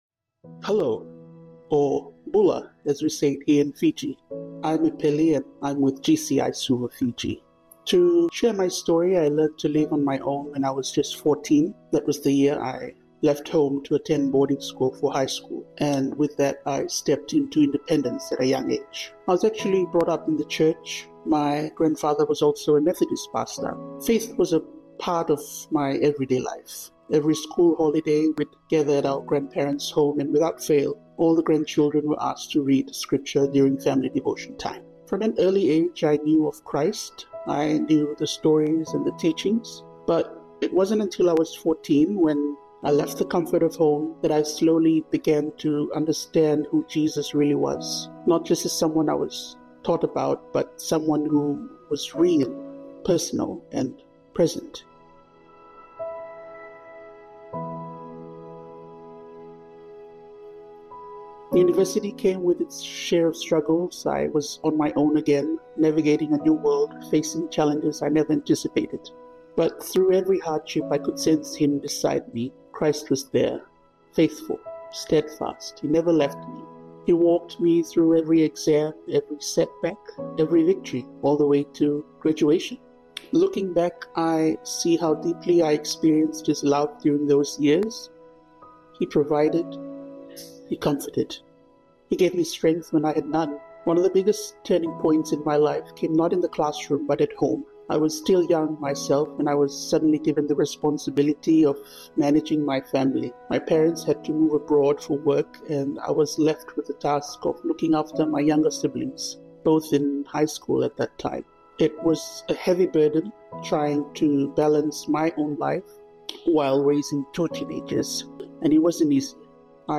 In this series, members from our global fellowship share testimonies of their experiences with Jesus through GCI congregations and ministries.